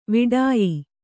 ♪ viḍāyi